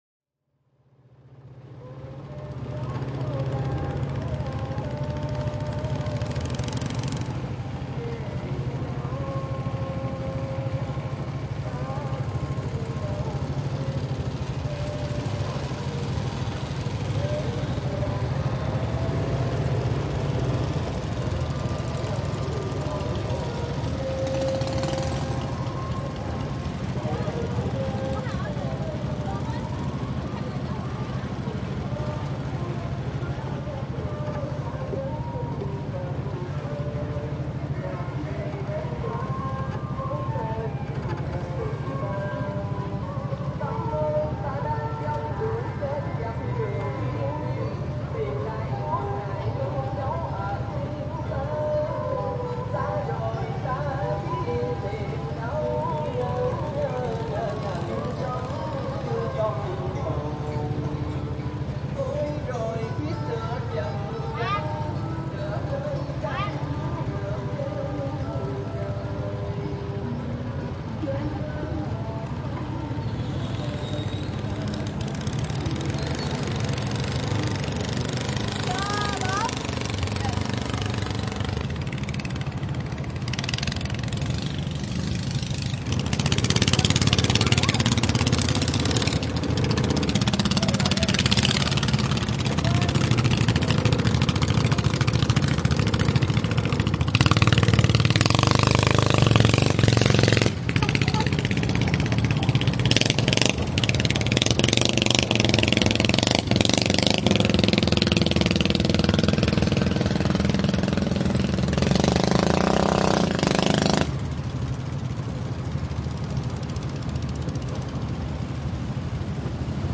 浮动市场
描述：2018年3月15日 探索越南南部芹苴附近的水上市场。现在是凌晨6点左右。
Tag: 小船 市场 越南 上午 亚洲 舷外机 发动机 现场录音 湄公河